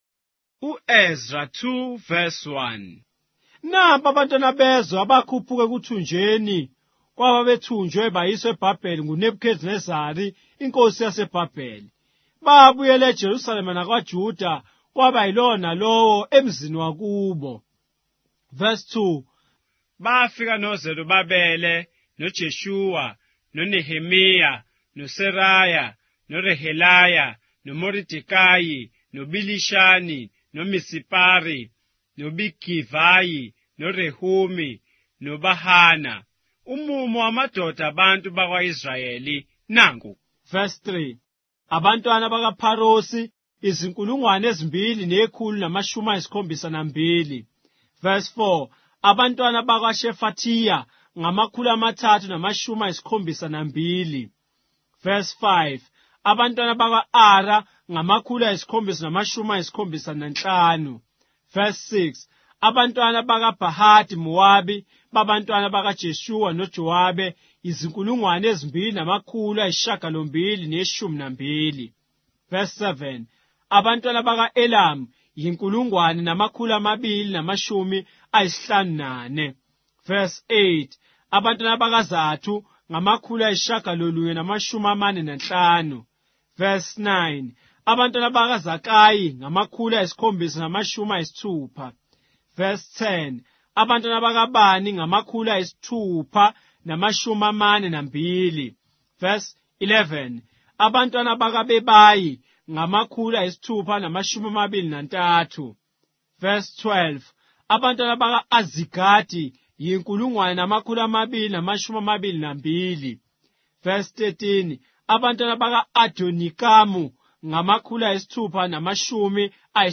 Ezra, chapter 2 of the Zulu Bible, with audio narration